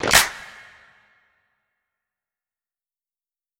Snare (11).wav